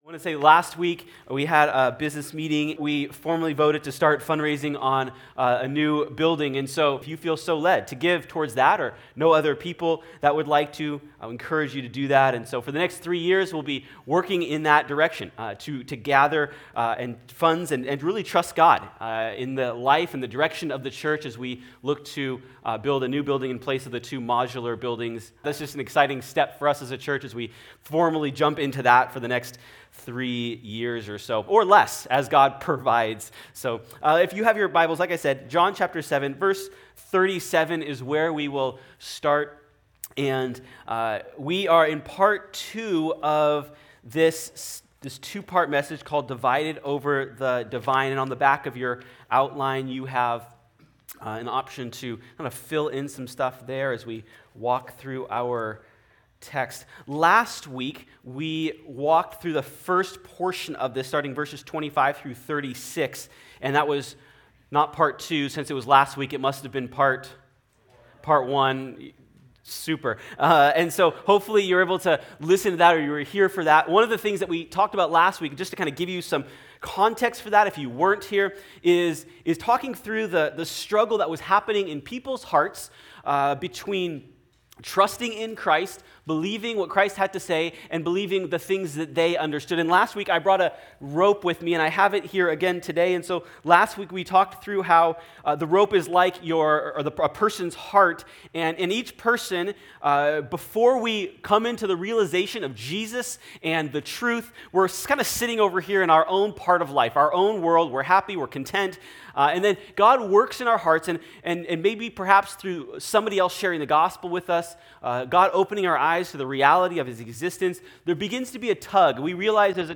Sermon Notes:Coming soon.